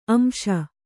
♪ amśa